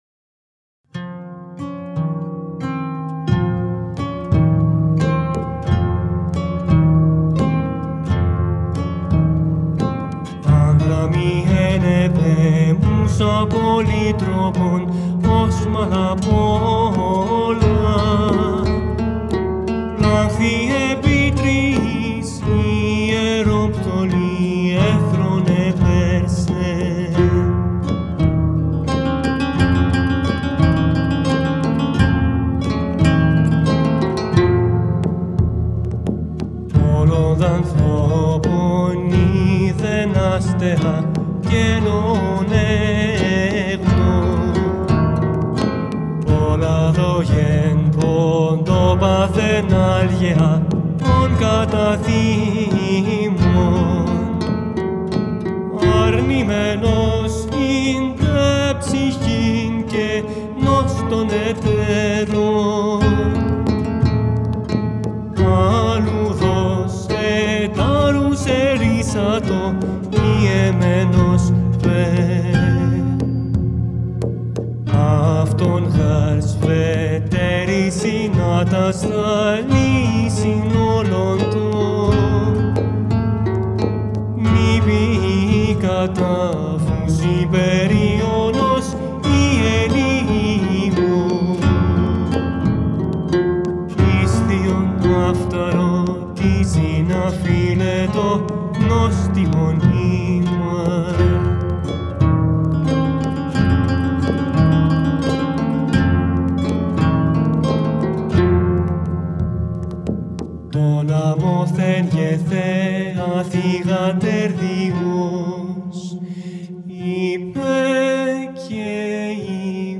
An Approach to the Original Singing of Homeric epics